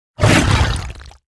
行为语音下载
攻击
SFX_LOE_009_Attack.ogg